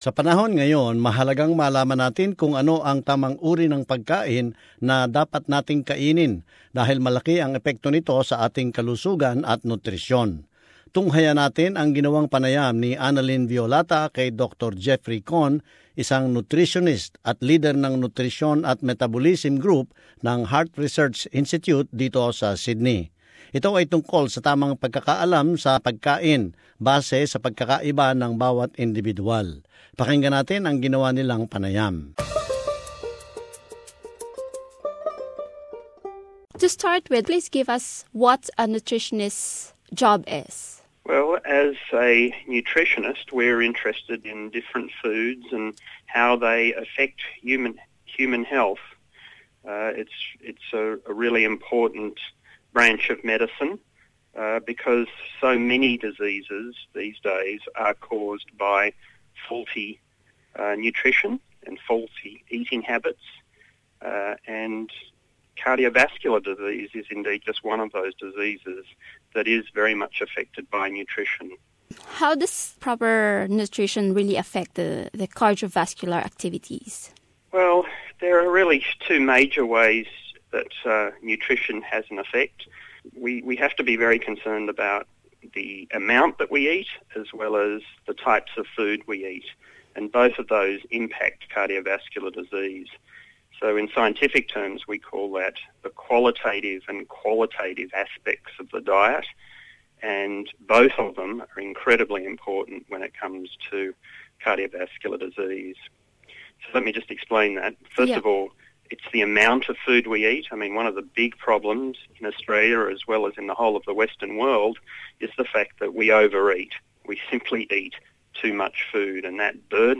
panayam